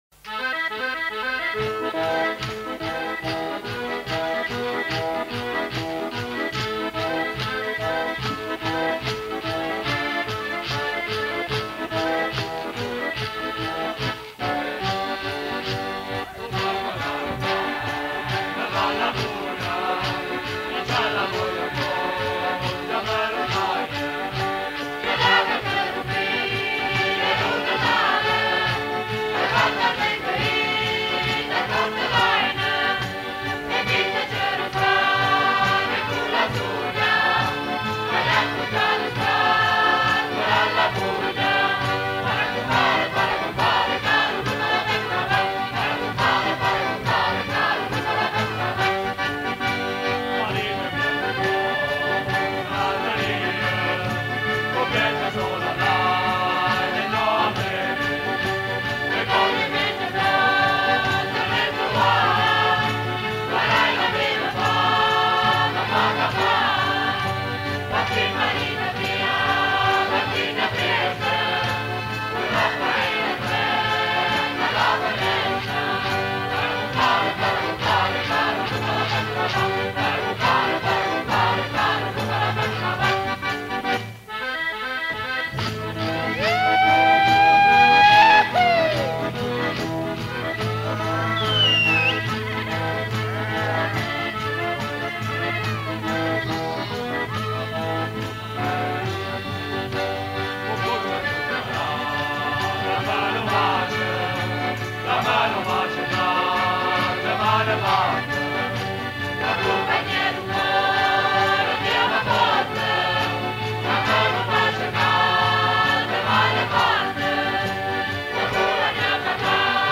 Parole e musica tradizionali
Esecuzione: Gruppo Folklorico Rintocco Molisano di Agnone